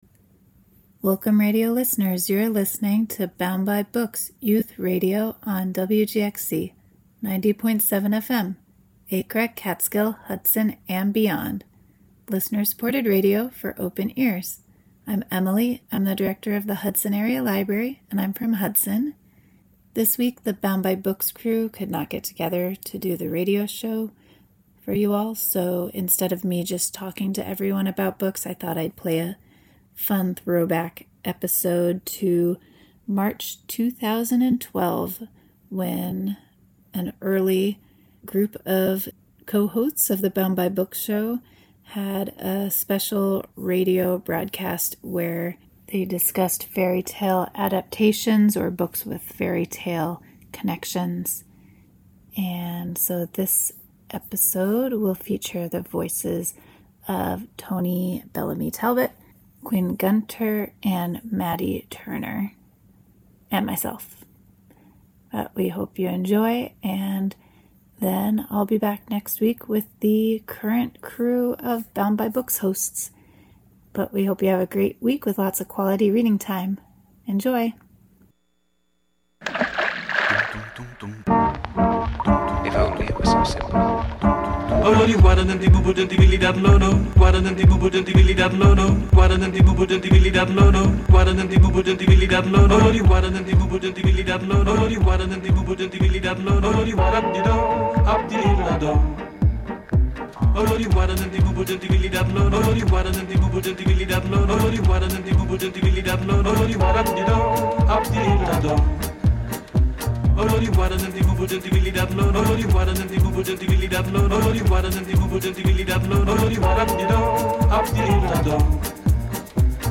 "Bound By Books" is hosted by tweens and teens